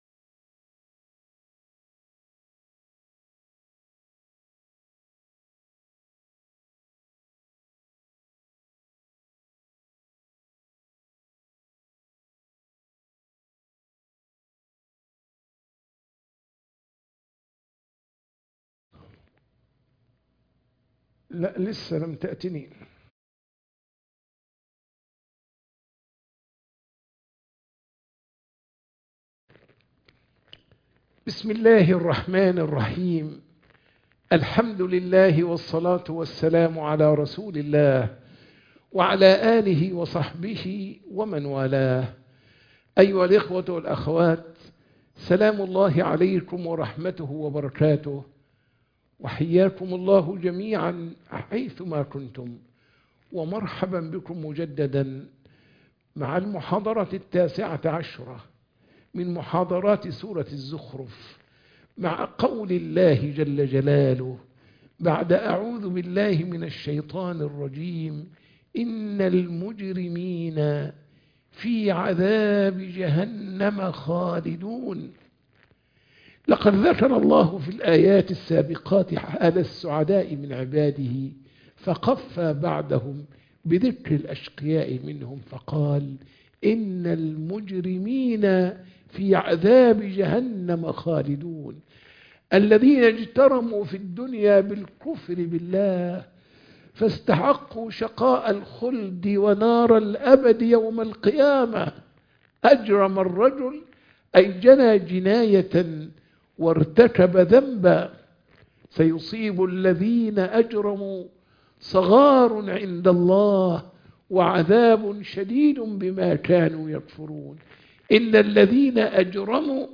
سورة الزخرف - المحاضرة 19